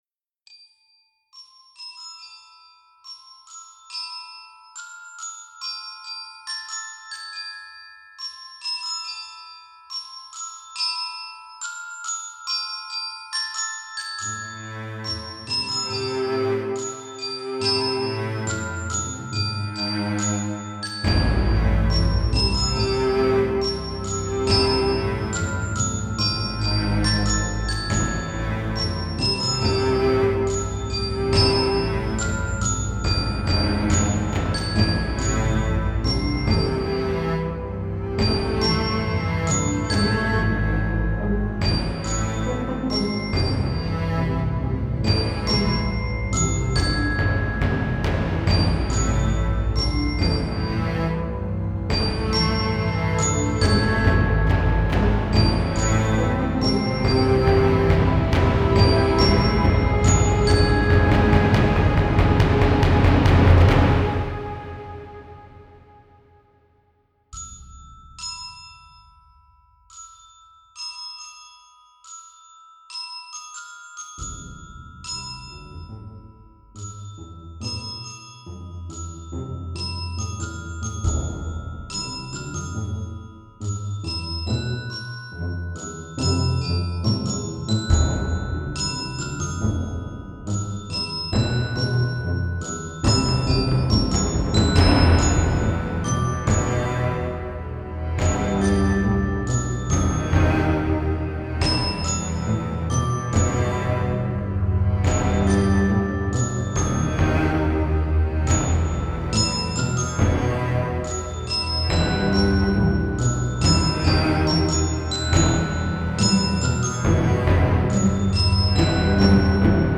Mysterious song I'll be using for the splash screen for my game. It's supposed to be indicative of a growing awareness that's approaching an epiphany but never quite reaches it.